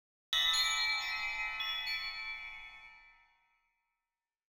AV_Bell_FX_02
AV_Bell_FX_02.wav